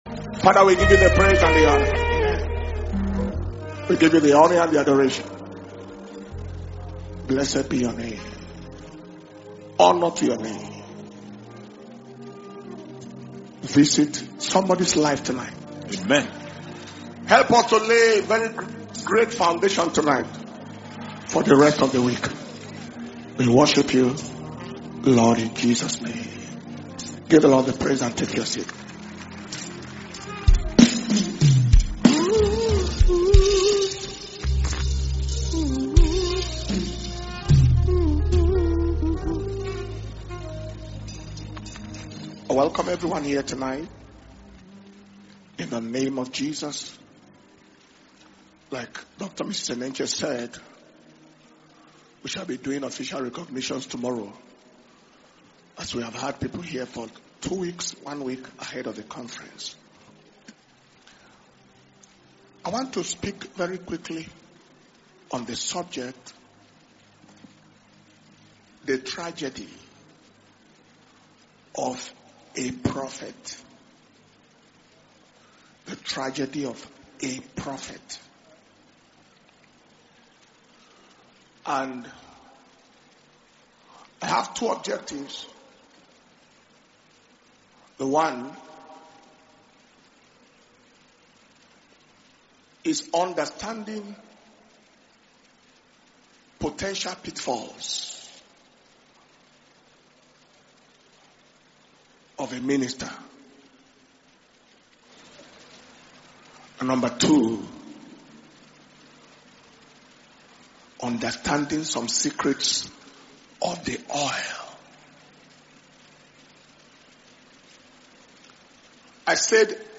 International Ministers’ Flaming Fire Conference (IMFFC 2025) August 2025